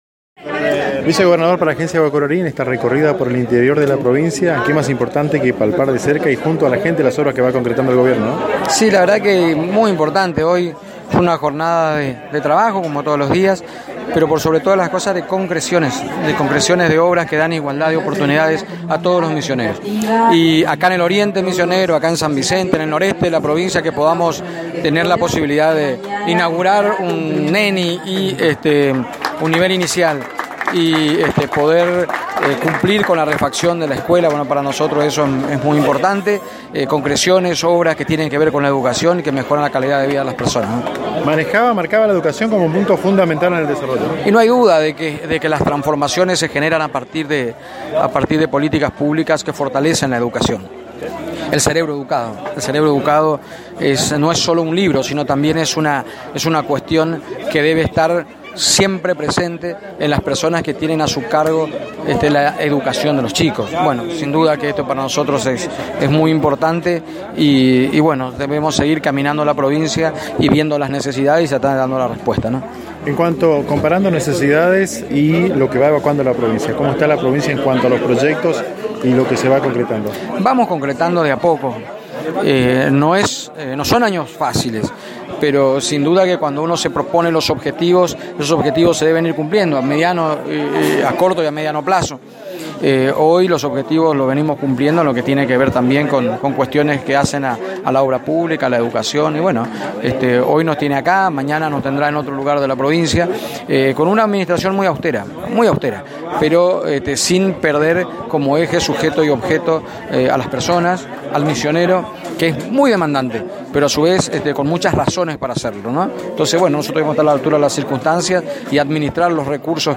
El vicegobernador realizó declaraciones exclusivas a ANG en la oportunidad de este grato acontecimiento.